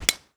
Golf Hit Driver.wav